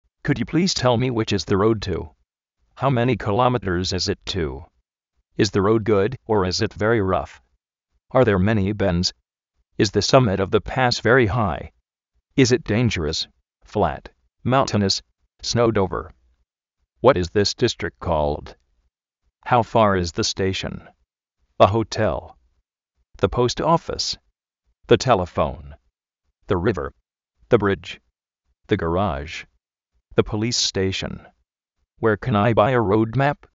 mótor-uéi (US: frí-uéi)
dúal kárrish-uéi
jáiuéi
méin róud, éi róud
síbra krósin
róuduérks ajéd